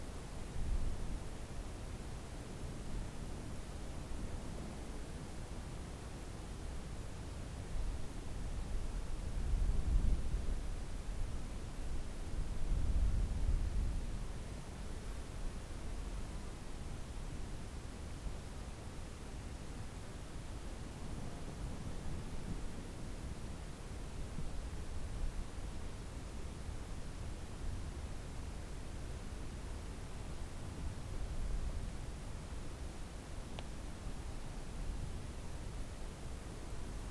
This article contains soundscapes from the web-based game Finding Gustavo.
desert-base.mp3